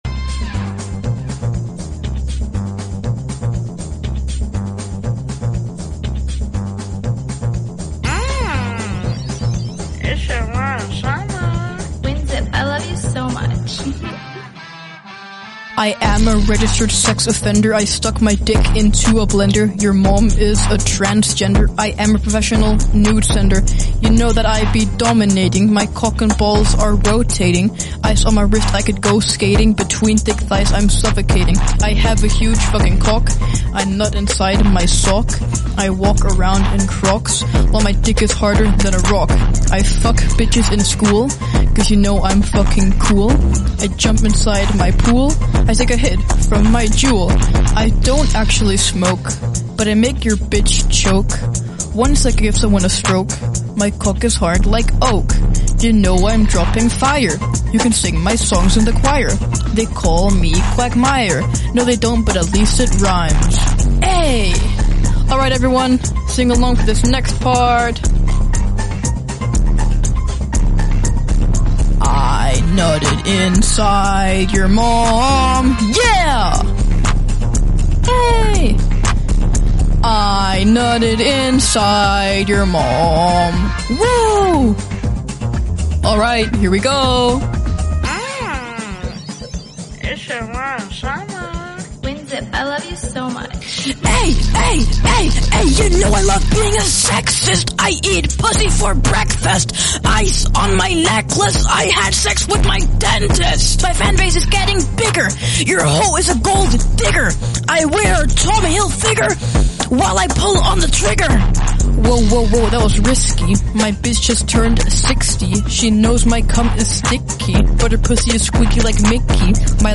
ahhhhh - Bouton d'effet sonore